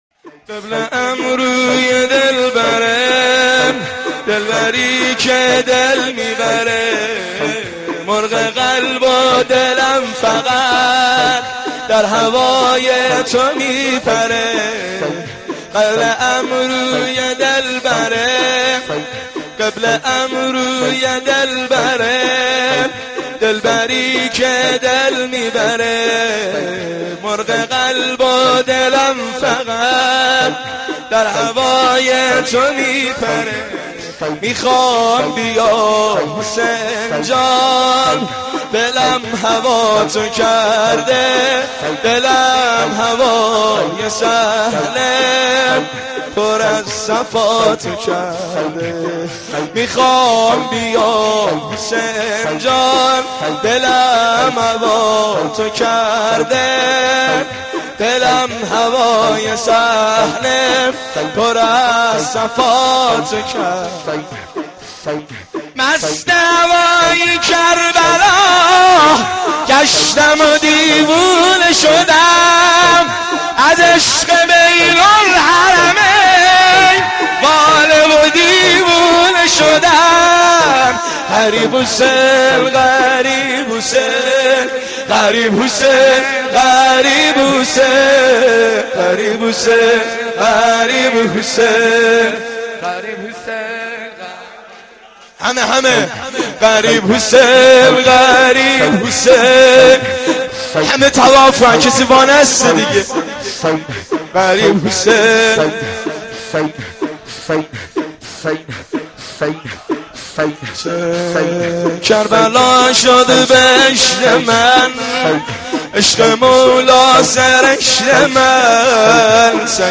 (شور - امام حسین علیه السلام)